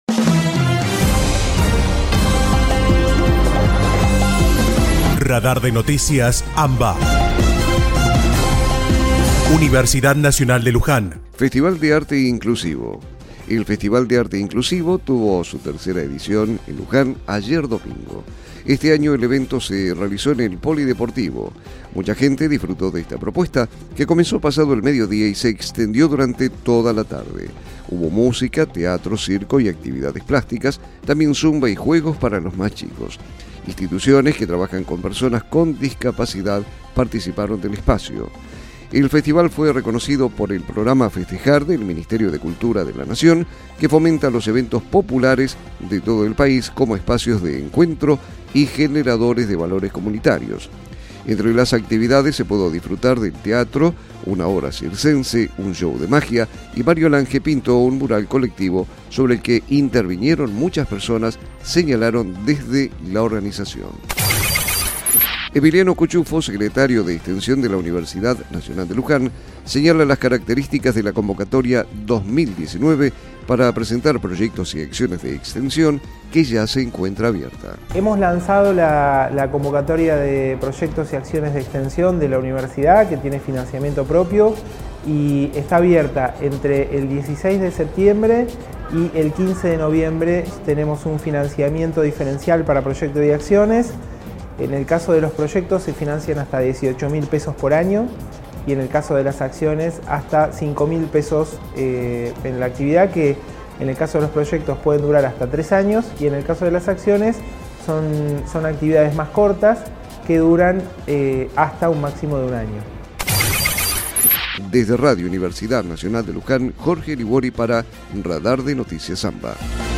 Panorama informativo «Radar de Noticias AMBA» , realizado de manera colaborativa entre las emisoras de las Universidades Nacionales de La Plata, Luján, Lanús, Arturo Jauretche, Avellaneda, Quilmes, La Matanza y General Sarmiento, integrantes de ARUNA (Asociación de Radiodifusoras Universitarias Nacionales Argentinas).